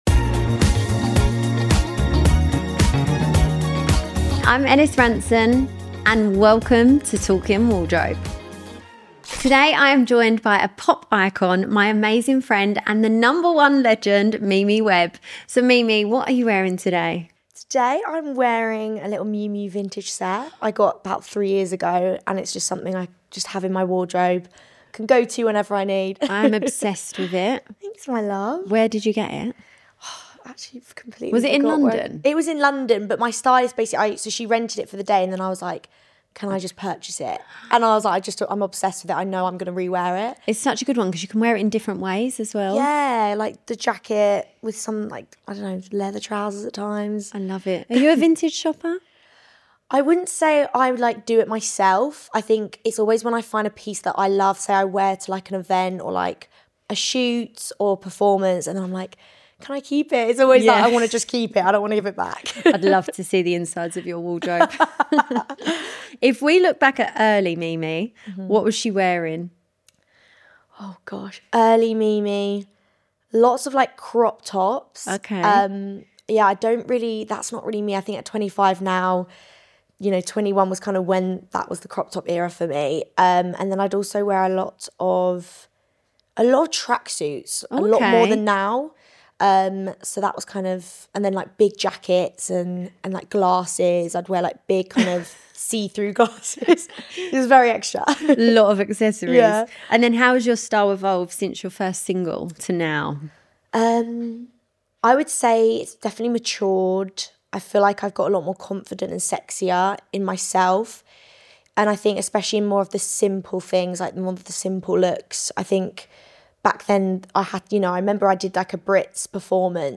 In this debut episode, I welcome the one and only legend, Mimi Webb, to my wardrobe to talk music, personal style, and the stories behind the looks.